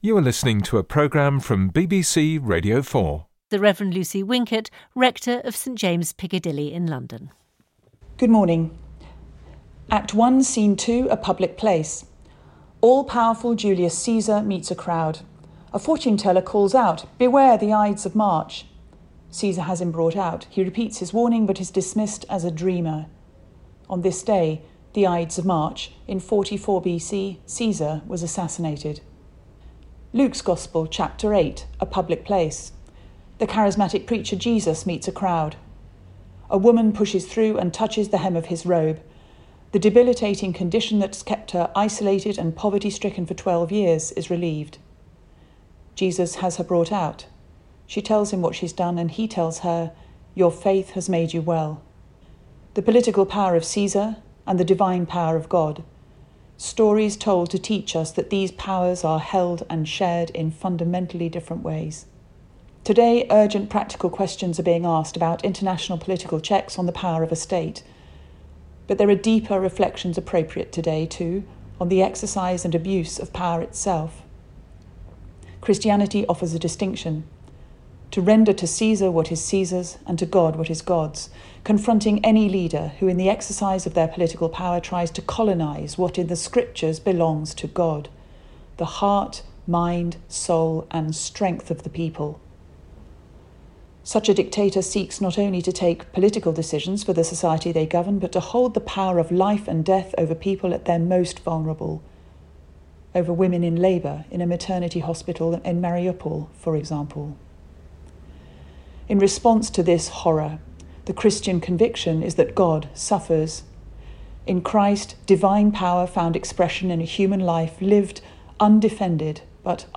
BBC Radio 4’s Religion & Spirituality podcast providing reflections from a faith perspective on issues and people in the news.